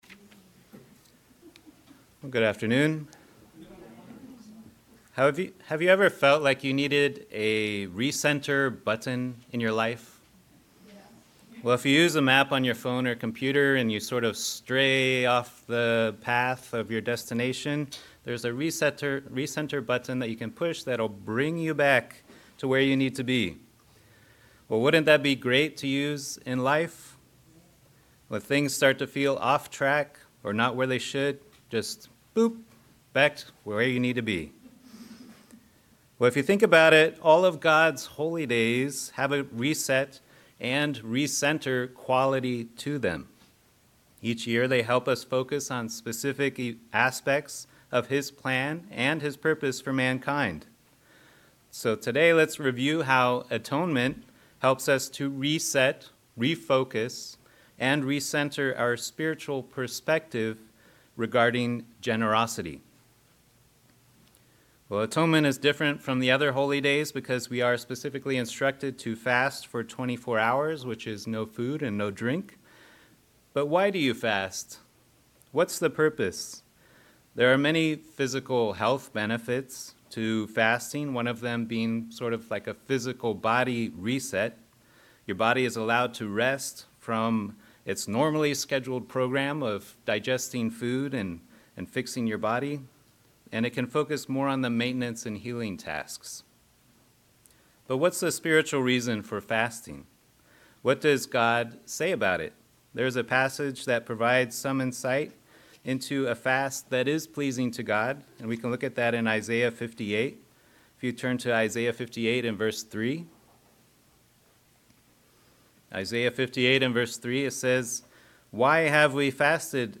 Sermons
Given in Northern Virginia